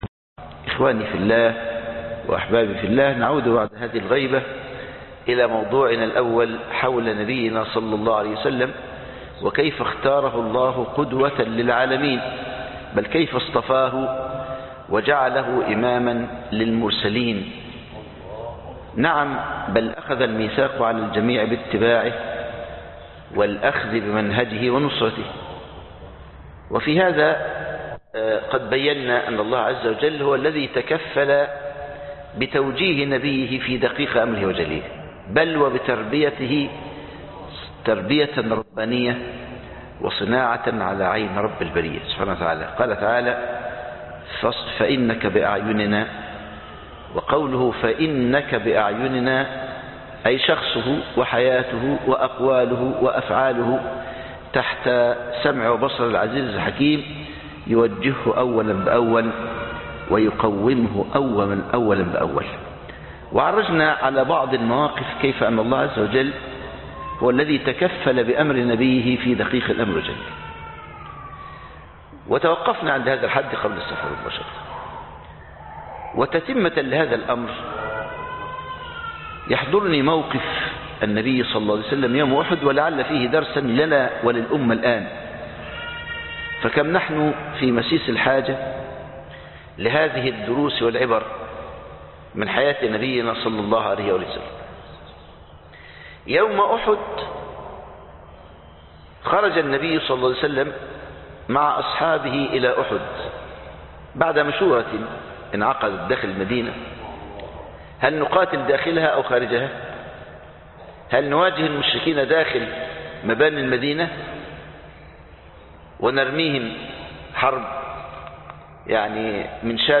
الوحي والتربية أحد 2 (مسجد العطية)